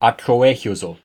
The House of Atloehyoszo (Pronounced